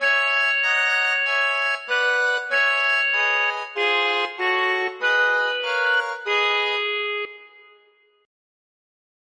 Simplicidade: tr�s exemplos complexos ou Complexidade: tr�s exemplos simples [ anterior ] [ pr�xima ] Capa Exemplo 1 Exemplo 2 Exemplo 3 [ ouvir ] e, � moda do �nicio do s�culo, adicione-se cor a cada uma das notas da varia��o, tornando-as fundamentais de tr�ades menores.